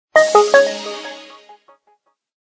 Power Off.mp3